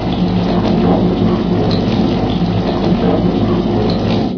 techage_reactor.ogg